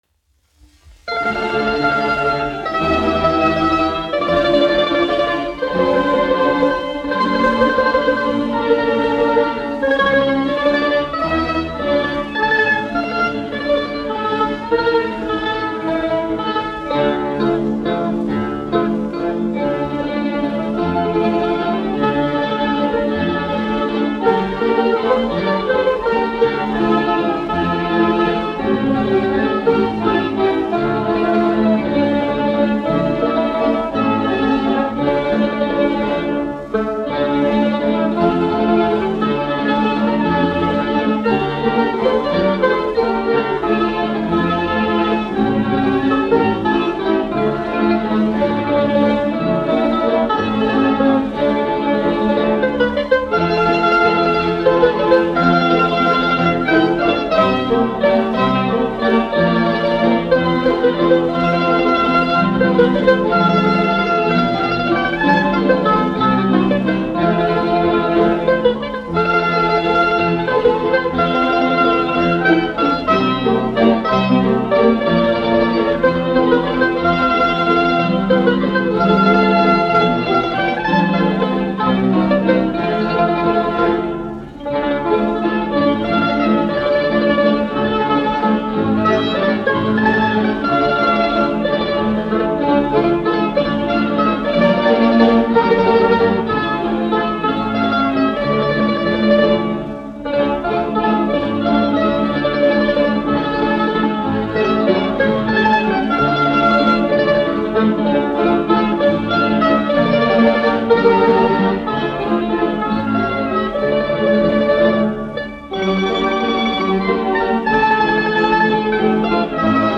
1 skpl. : analogs, 78 apgr/min, mono ; 25 cm
Orķestra mūzika
Skaņuplate